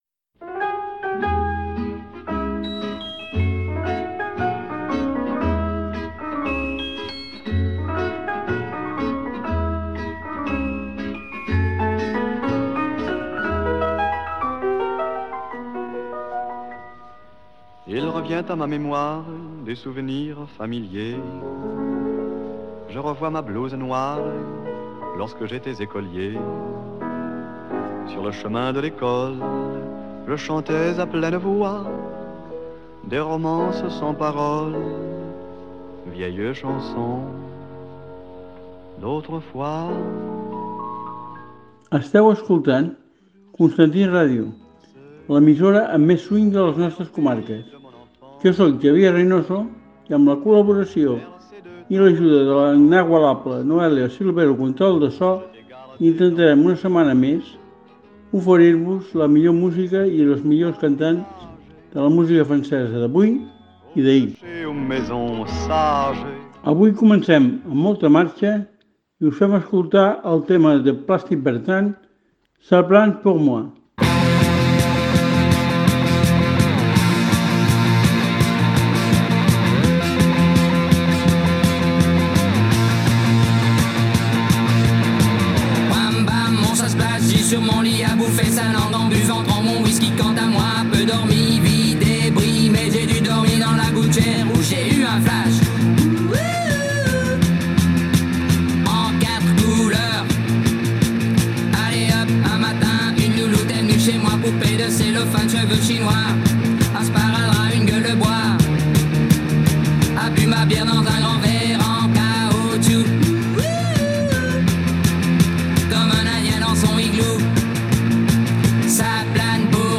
Un espai on descobrirem cada dimecres els cantants més famosos de la cançó francesa. Deixa’t seduir per aquests sons màgics que et transportaran al París més bohemi i seductor sense moure’t de casa!